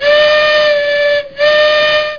steamblo.mp3